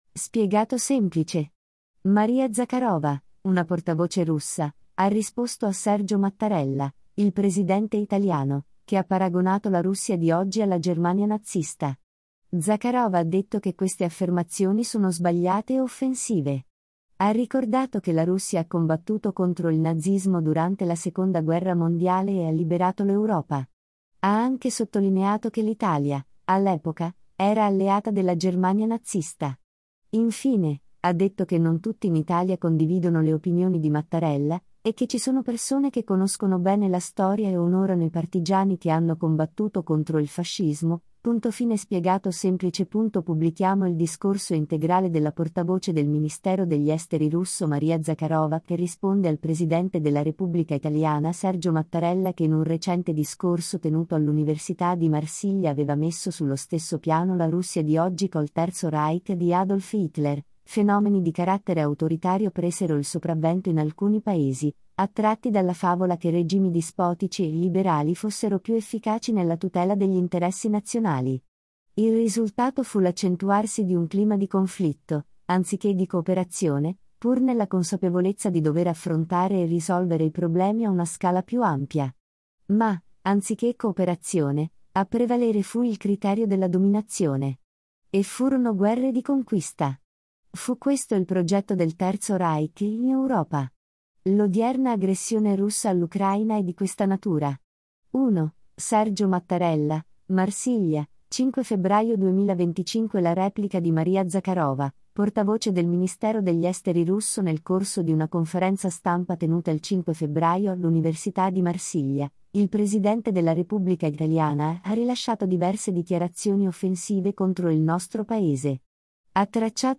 La replica di Maria Zhakarova, portavoce del ministero degli Esteri russo